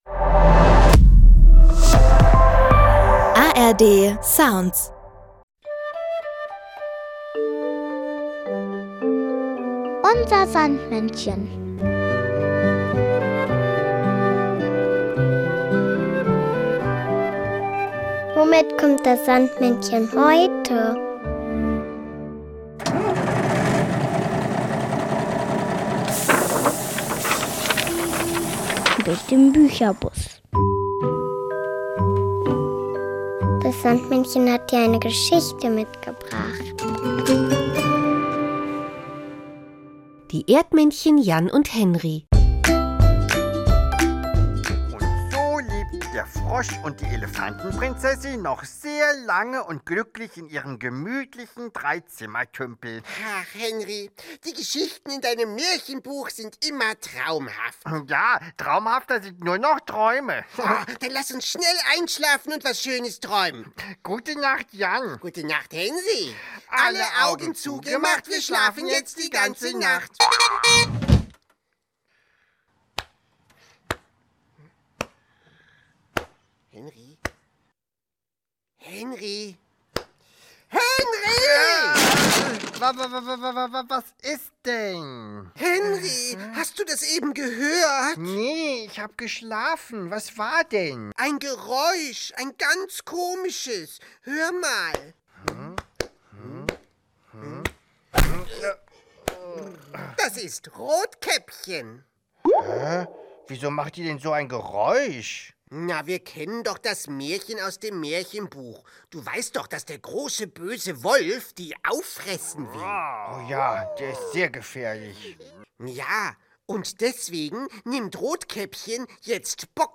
das Kinderlied